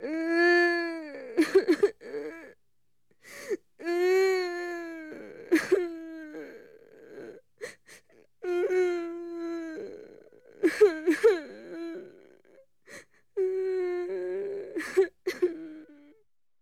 HumanCry 1016_24.wav